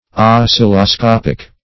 -- os*cil"lo*scop`ic, a.